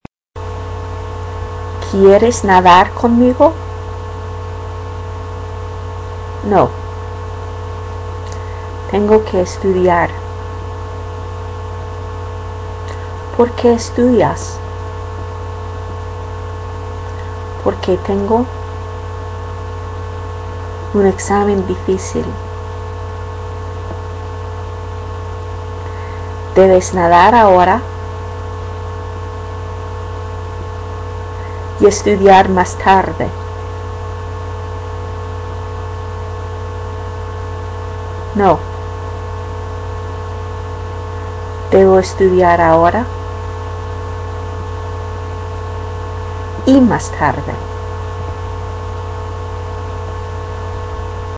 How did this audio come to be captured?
Instructions: You will hear the dialogue broken up into parts for you to imitate.